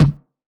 VEC3 Percussion 030.wav